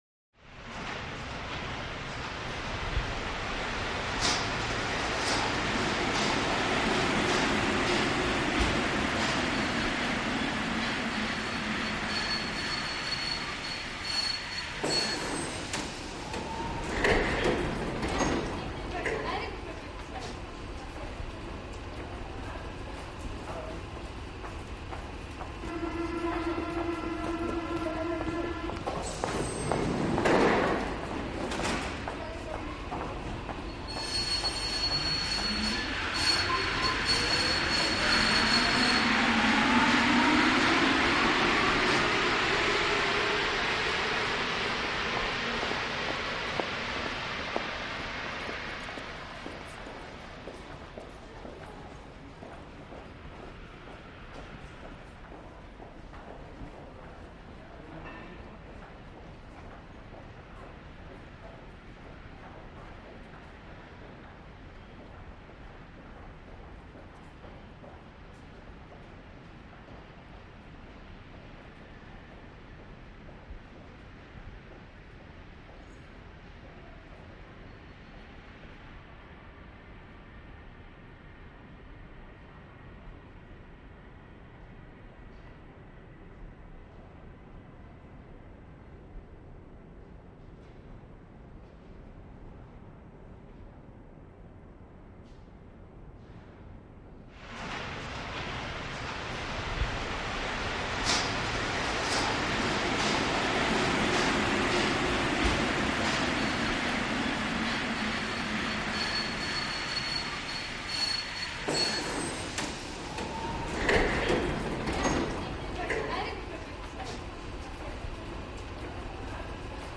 Train Station - European Station